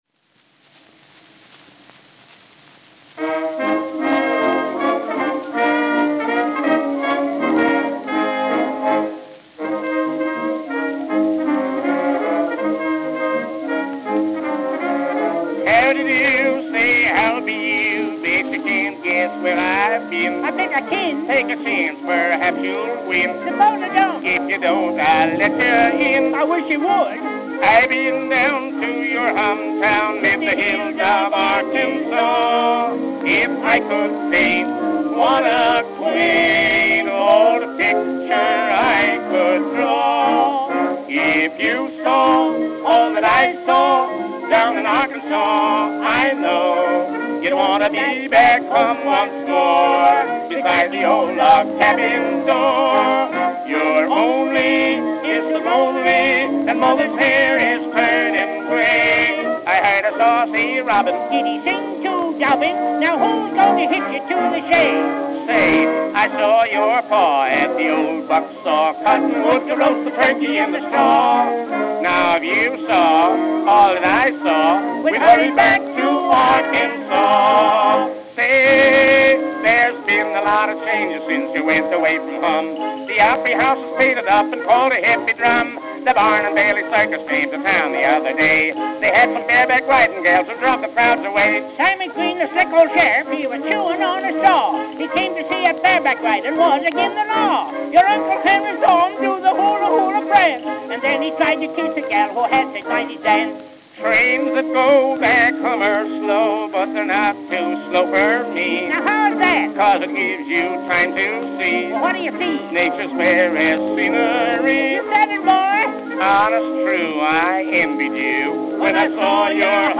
Victor 10-Inch Double-Sided Acoustical Records